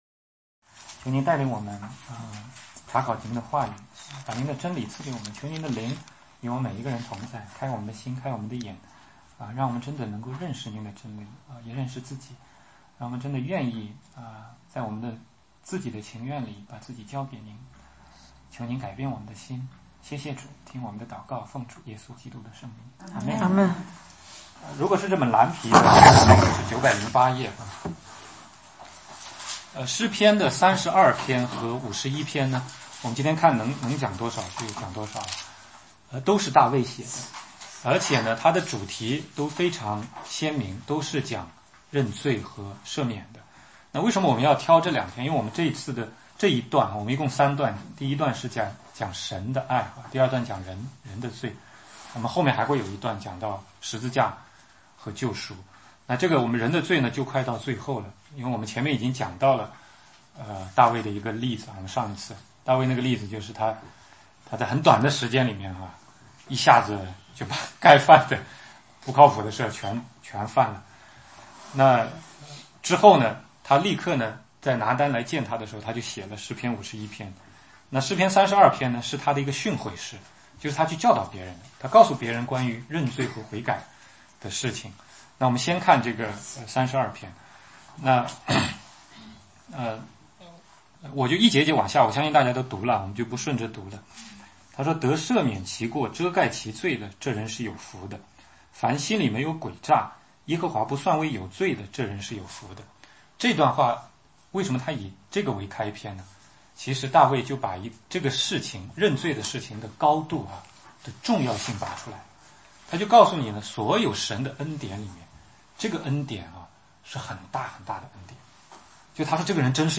16街讲道录音 - 认罪与赦免